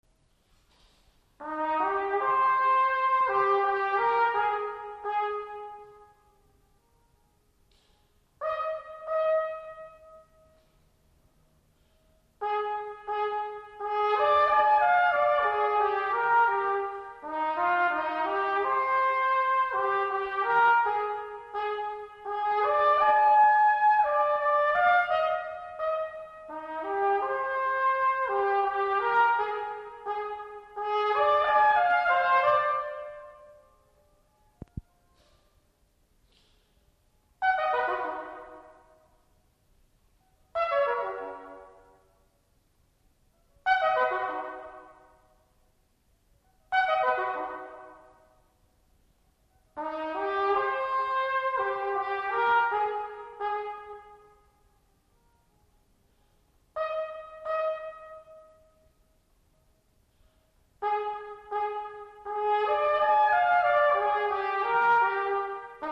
trait d'orchestre 10   Starvinsky pétrouscka solo travail